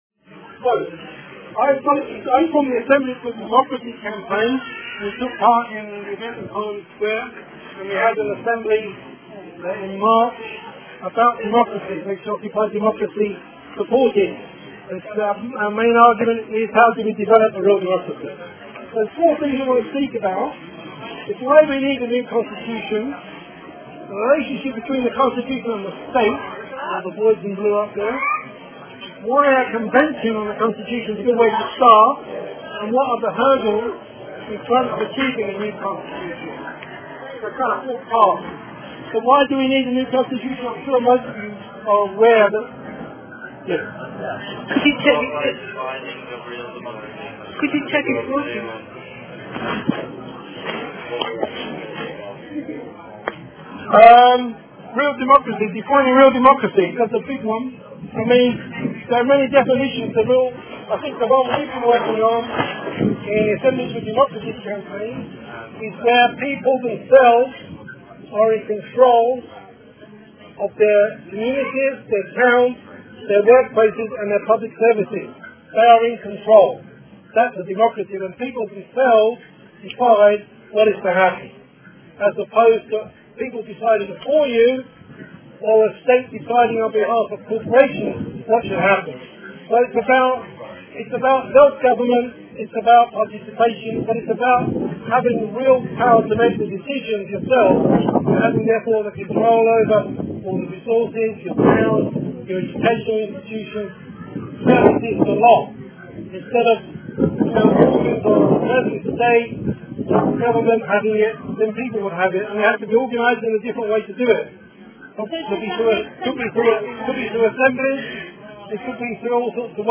Asked by Occupy Democracy to Runnymede “Festival For Democracy” to speak about a citizens’ convention on the constitution, this cut no ice with police forces assembled from Surrey, Sussex and Somerset.